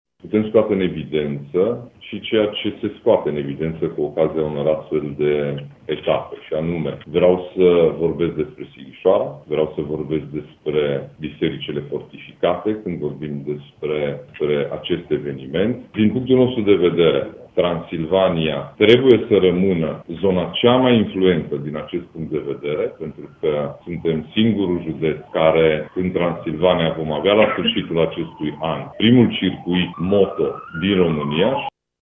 Judeţul Mureş va fi, până la sfârşitul acestui an, singurul oraş cu un circuit dedicat sporturilor cu motor, spune preşedintele Consiliului Judeţean Mureş, Ciprian Dobre: